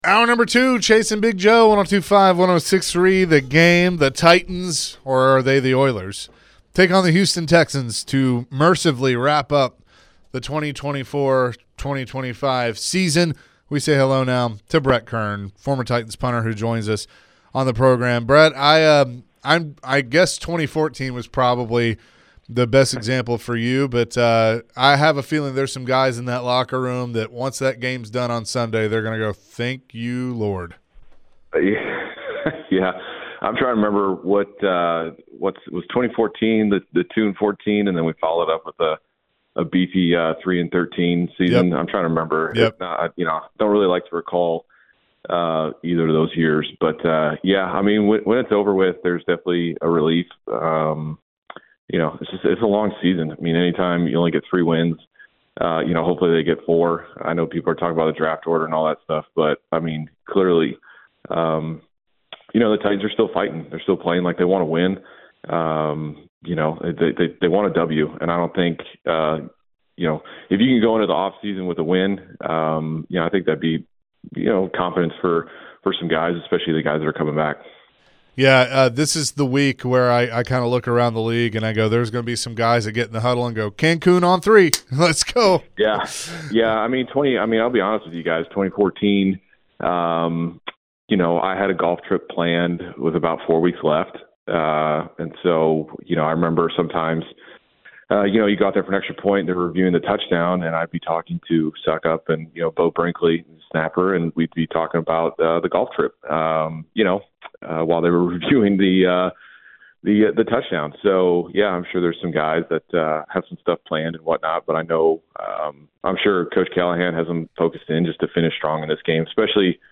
Former Titans punter Brett Kern joined the show and shared his thoughts on the Titans QB situation. Brett also went on to tell a funny story about the one moment he was scared for his life playing football.